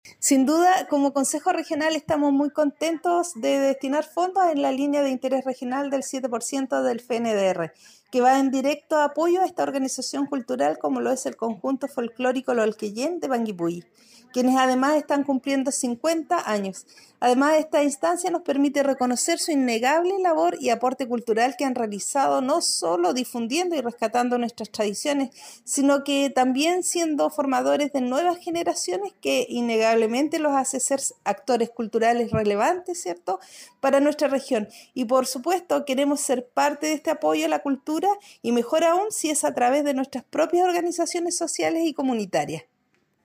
Consejera-Ximena-Castillo1.mp3